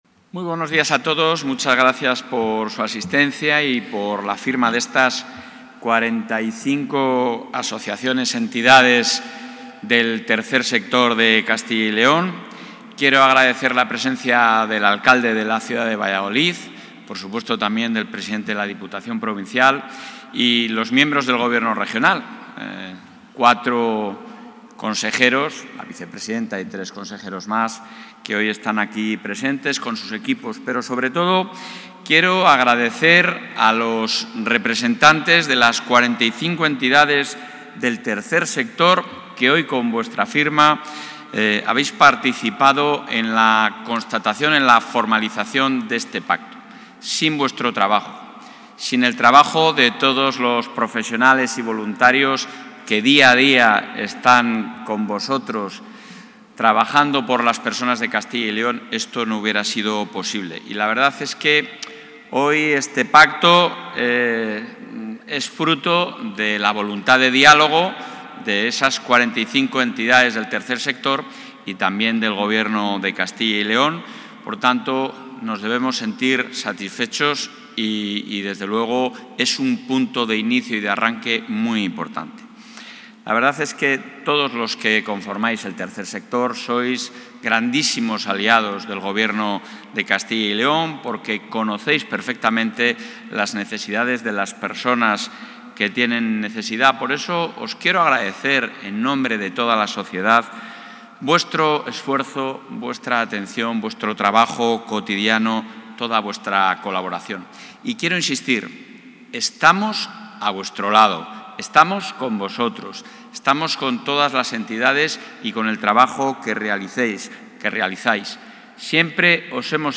Audio con la intervención de Mañueco.